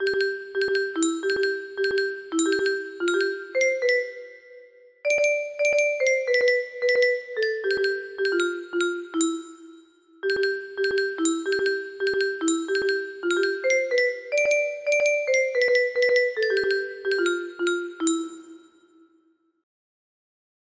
Clone of X max music box melody